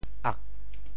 “恶”字用潮州话怎么说？